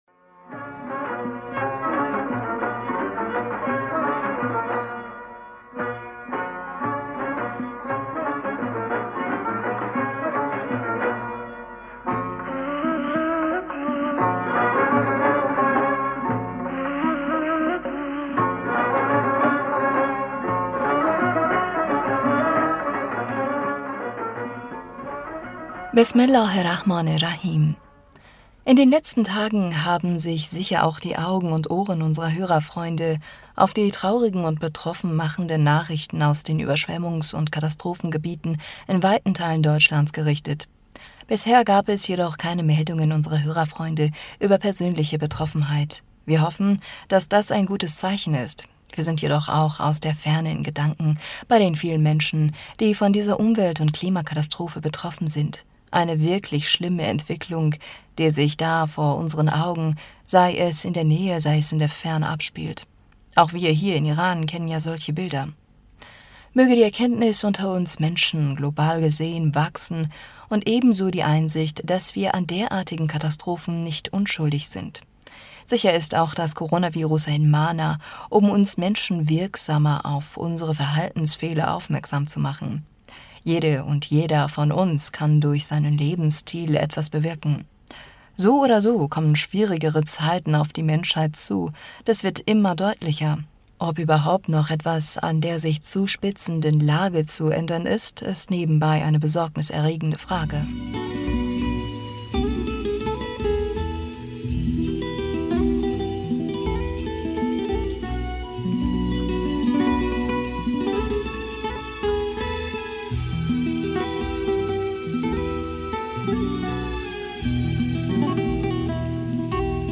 Hörerpostsendung am 18. Juli 2021 Bismillaher rahmaner rahim - In den letzten Tagen haben sich sicher auch die Augen und Ohren unserer Hörerfreunde auf di...